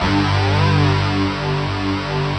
Index of /90_sSampleCDs/Optical Media International - Sonic Images Library/SI1_DistortGuitr/SI1_DistGuitrMut